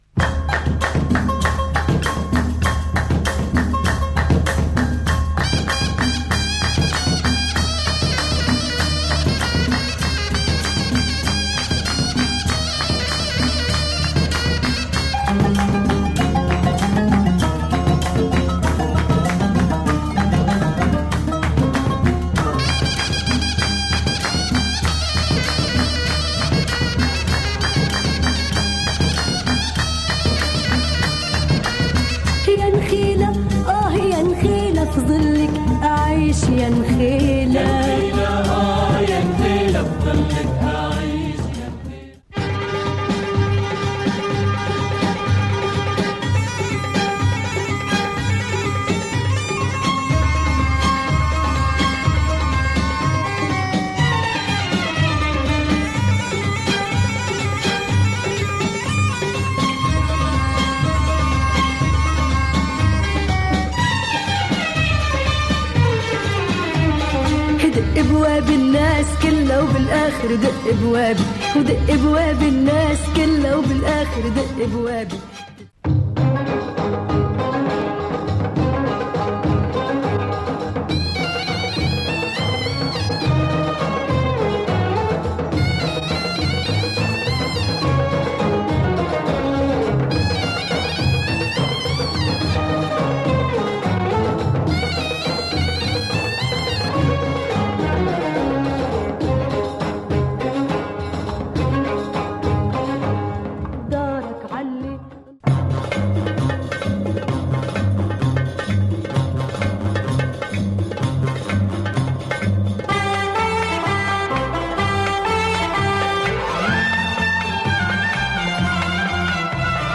Beautiful Lebanese music
Killer oriental beats on the whole album.